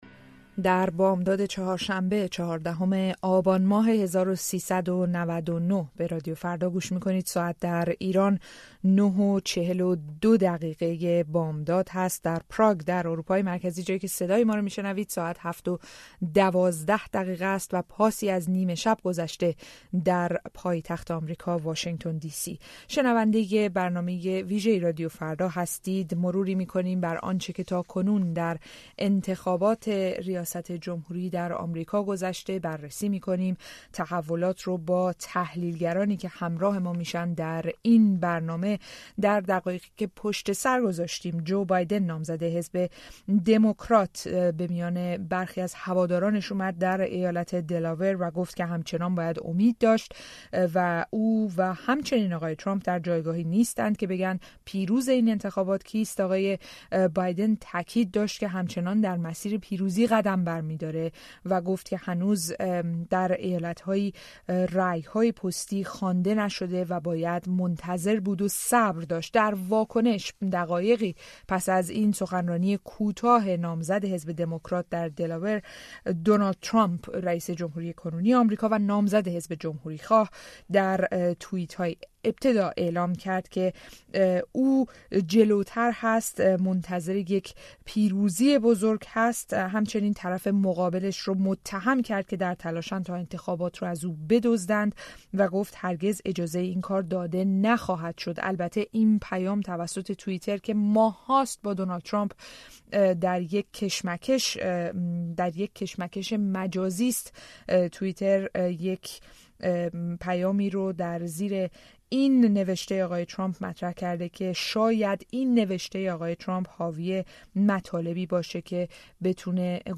علیرغم آنچه مقام‌های جمهوری اسلامی در روزهای منتهی به انتخابات ریاست جمهوری آمریکا مطرح کردند، بسیاری از ایرانیان، انتخاباتی را که روز سه‌شنبه برگزار شد، لحظه به لحظه دنبال کردند و از اهمیت این انتخابات برای آینده ایران در شبکه‌های اجتماعی نوشتند. رادیو فردا برای بررسی تاثیر این انتخابات و پیروزی یکی از دو نامزد دمکرات و جمهوری‌خواه نظر چهار تحلیلگر را جویا شده است.